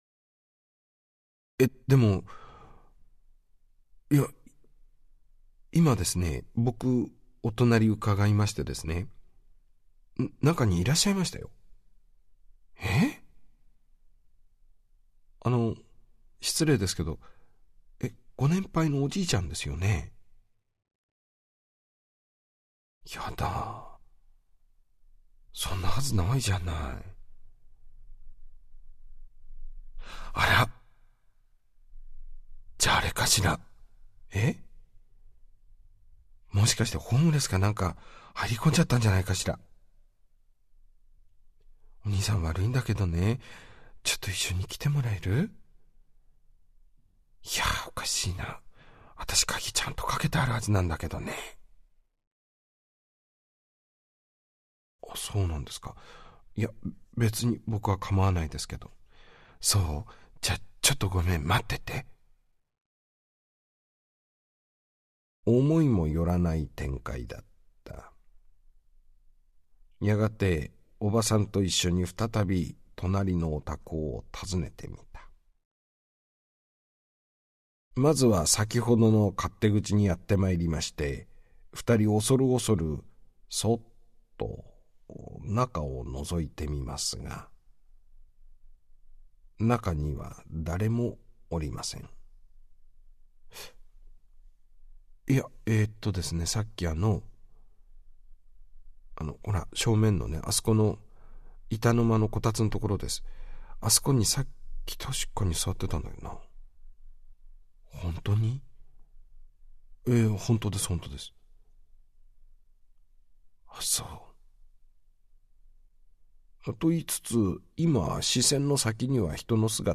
落語や講談にも似た独特な語り節